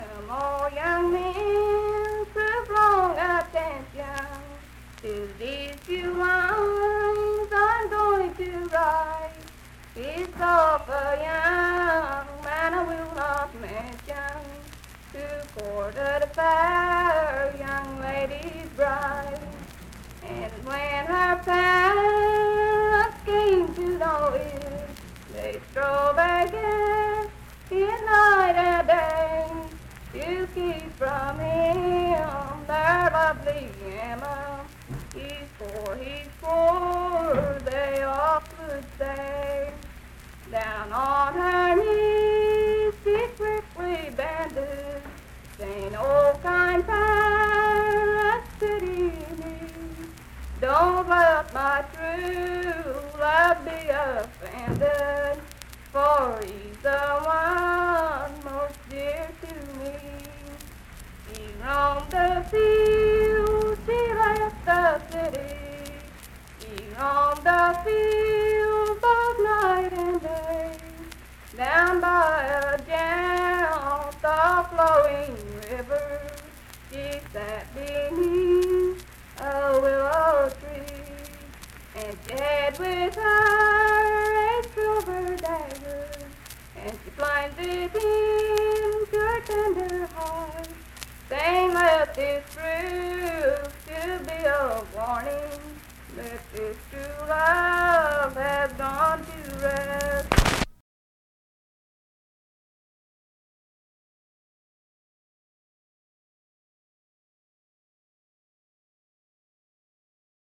Unaccompanied vocal performance
Verse-refrain 9(4).
Voice (sung)